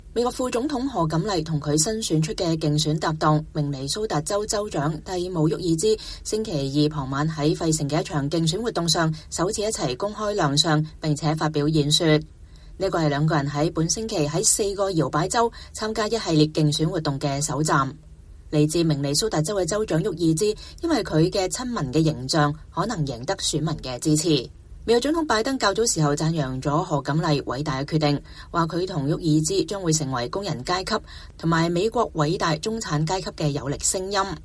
美國副總統賀錦麗 (又譯卡瑪拉·哈里斯Kamala Harris) 和她新選出的競選搭檔、明尼蘇達州州長蒂姆·沃爾茲(Tim Walz)星期二(8月6日)傍晚在費城的一場競選活動上首次一起公開亮相並發表演說。